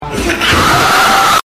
Grandma Scream Sound Effect Free Download
Grandma Scream